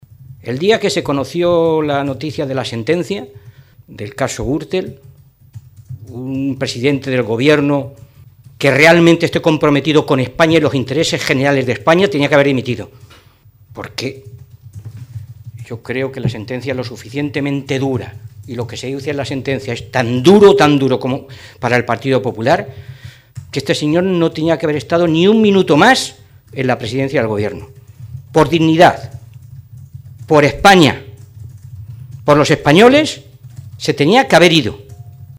El diputado del Grupo socialista, Fernando Mora, ha señalado hoy que les guste o no a los dirigentes del PP la moción de censura que ha presentado el PSOE está siendo requerida por la mayoría de los españoles, “porque la mayoría de los ciudadanos censuran la actuación de los ‘populares’”.
Cortes de audio de la rueda de prensa